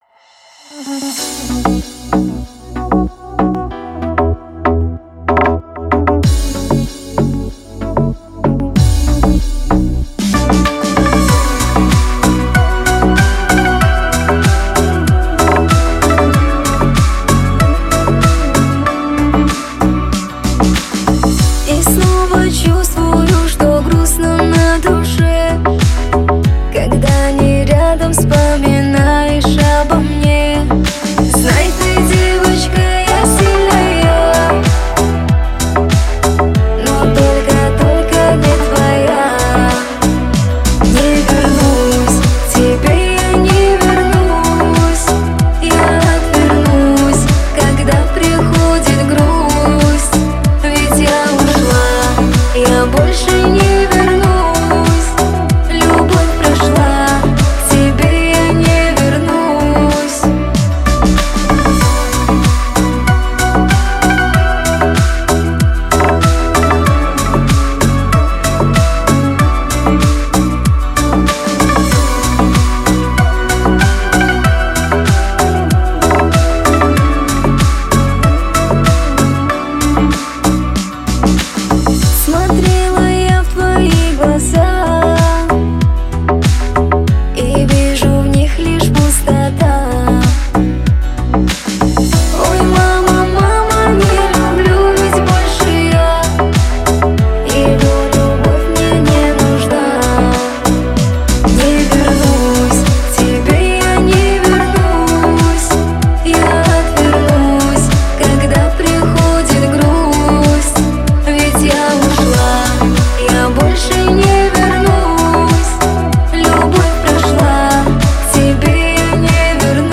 Категория: Шансон песни
красивый шансон